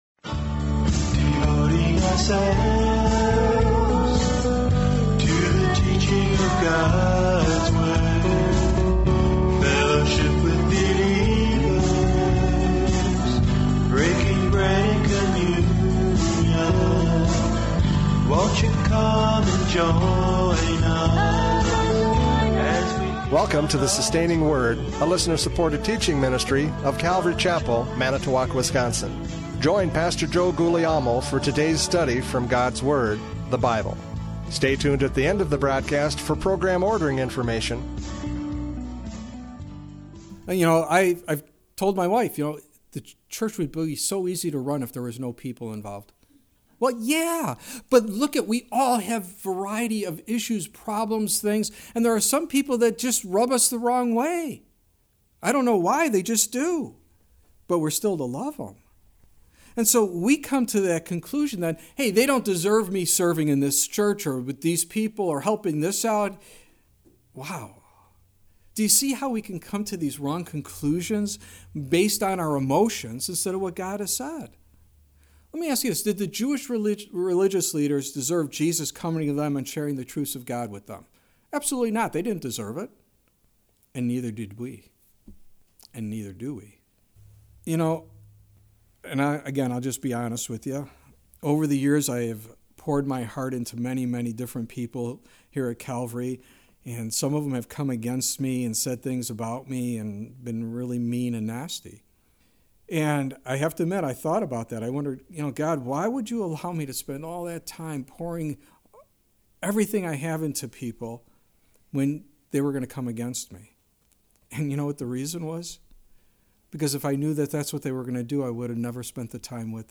John 11:4-16 Service Type: Radio Programs « John 11:4-16 Roadblocks to Serving!